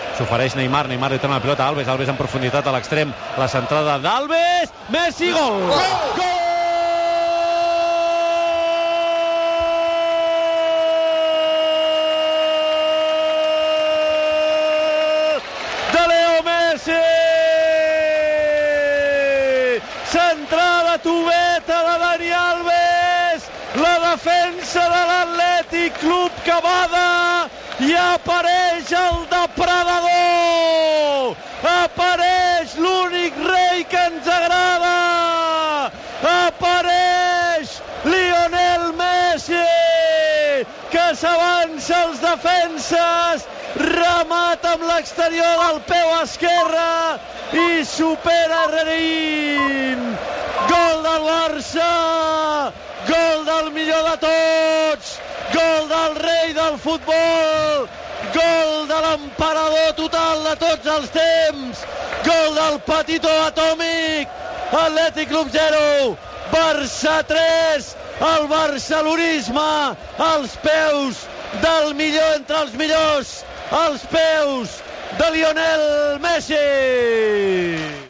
Transmissió del partit de la Copa del Rei de futbol masculí entre l'Athletic Club i el Futbol Club Barcelona.
Narració del segon gol de Leo Messi.
Esportiu